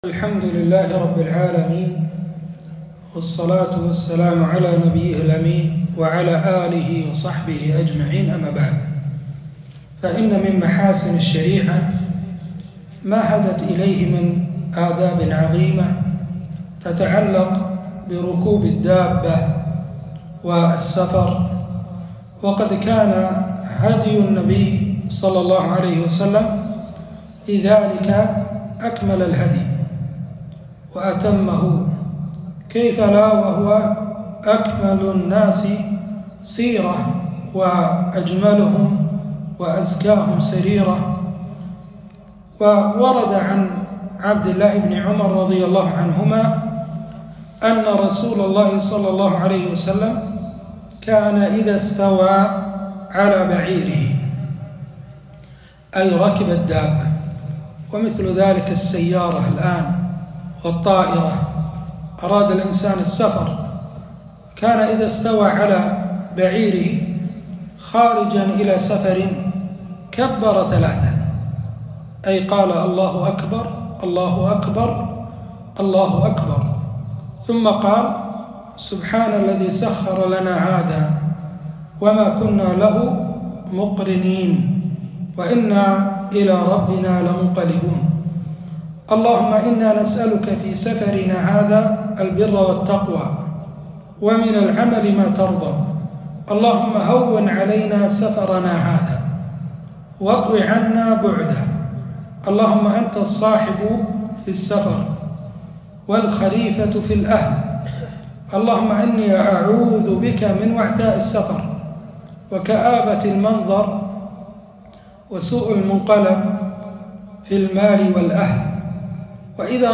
كلمة - شرح دعاء السفر